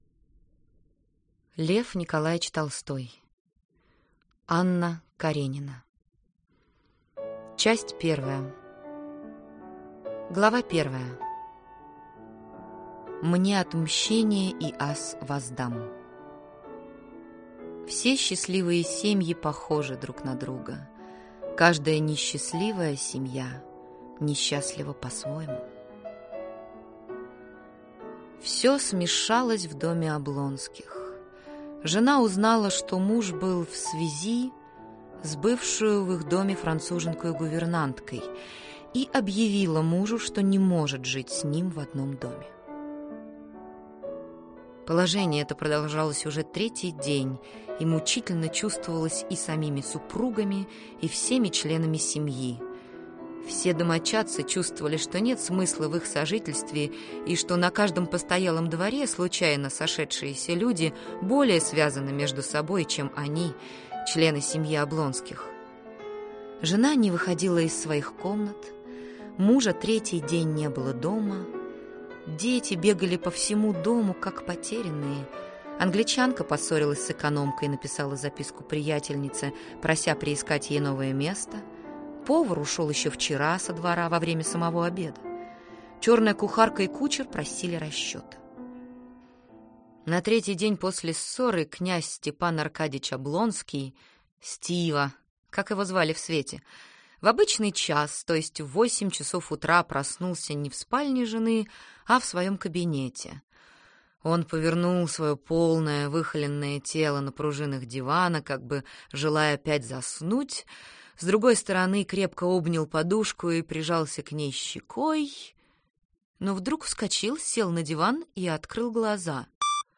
Аудиокнига Анна Каренина (в сокращении). Часть 1 | Библиотека аудиокниг